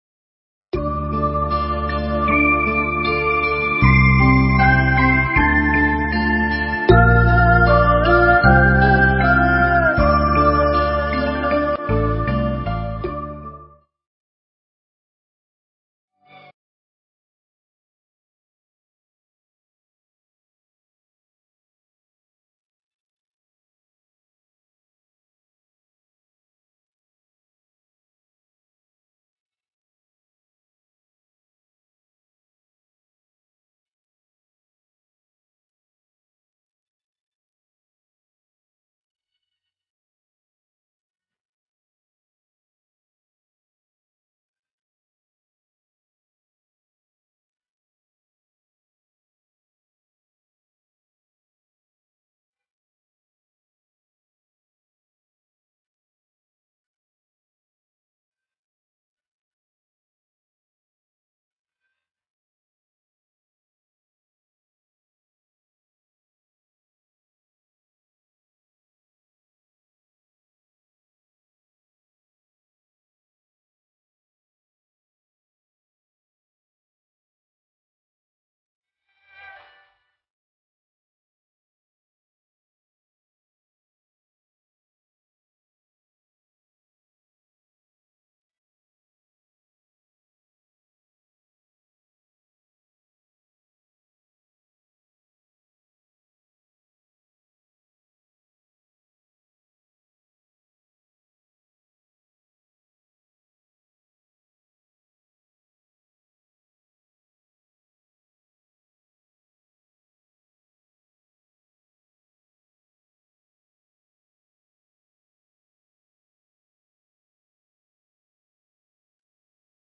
Mp3 Pháp Thoại Tu Là Nguồn An Vui Miên Viễn – Hòa Thượng Thích Thanh Từ giảng tại Chùa Đức Viên, ngày 16 tháng 11 năm 2002